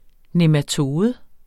Udtale [ nemaˈtoːðə ]